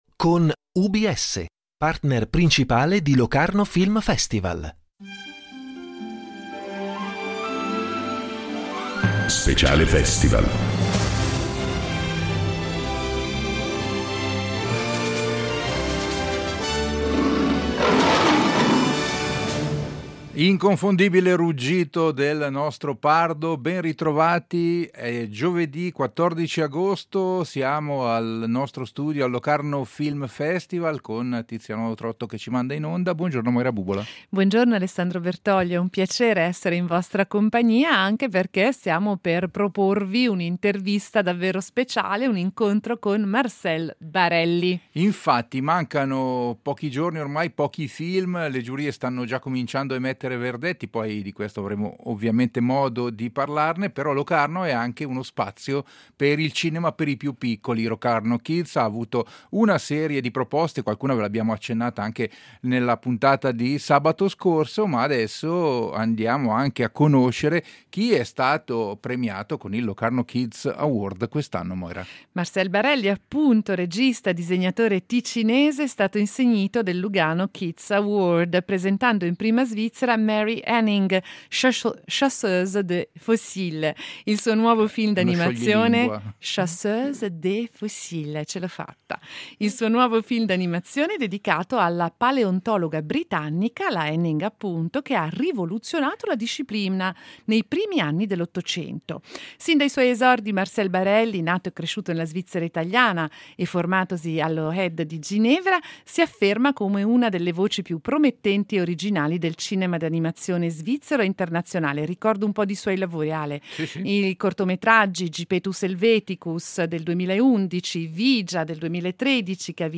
In diretta dal Locarno Film Festival